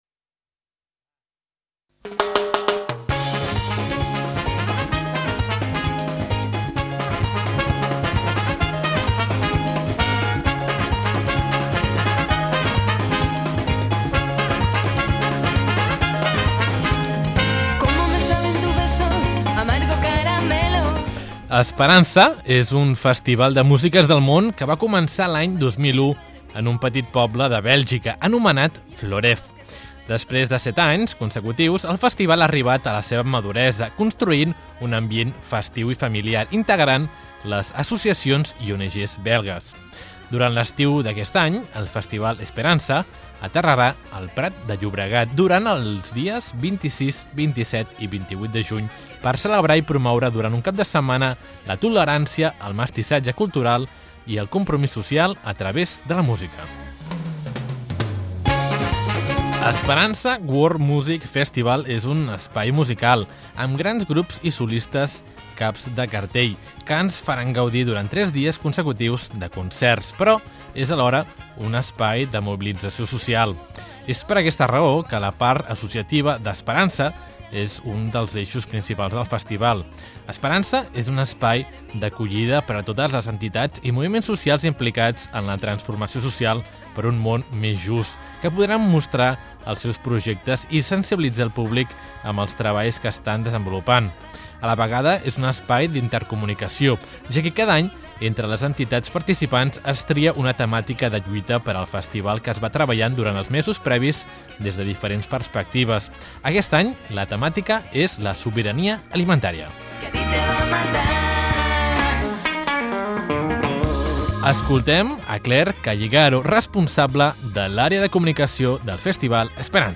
Noticias :: Reportajes radiofónicos :: ESPERANZAH!